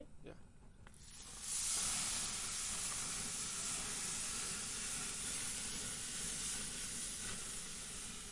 烧烤三文鱼
Tag: 烧烤 牛排 滋滋声 烧烤